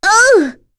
Rodina-Vox_Damage_03.wav